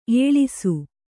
♪ ēḷisu